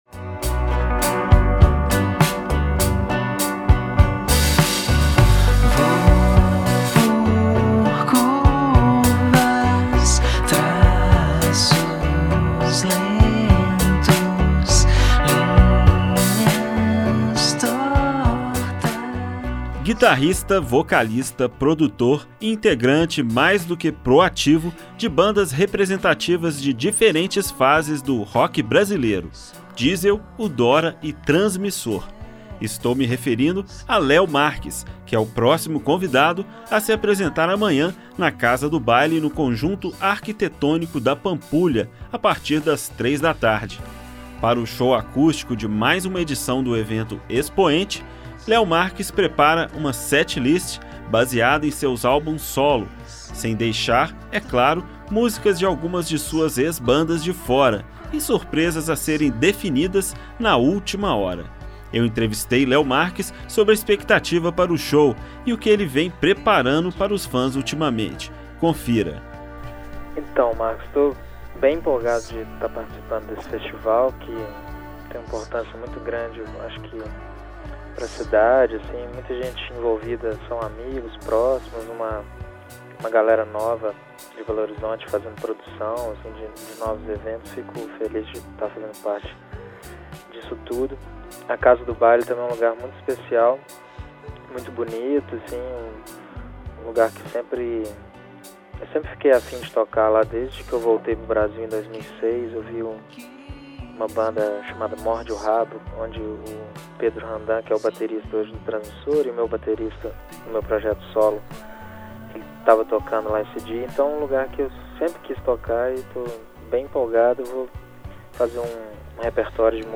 Além disso, conversamos sobre influências, mudanças de rumos na carreira e projetos. Confira na íntegra a entrevista